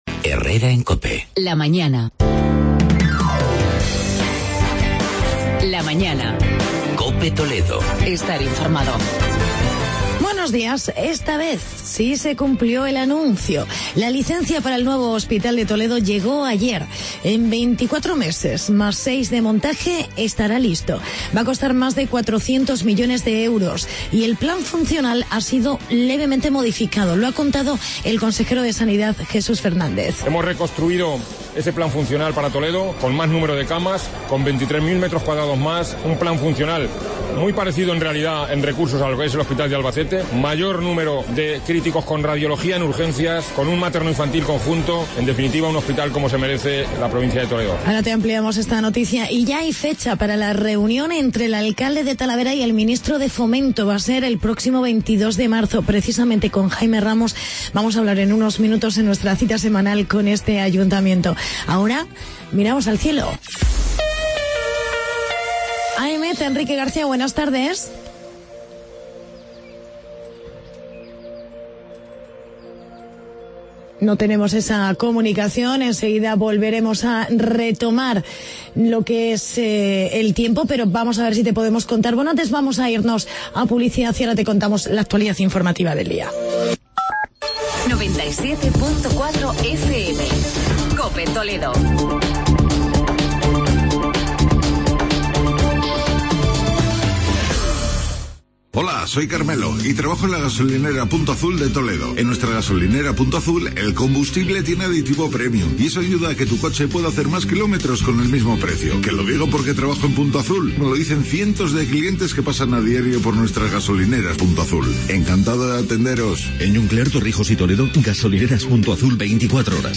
Actualidad y entrevista con el alcalde de Talavera de la Reina, Jaime Ramos.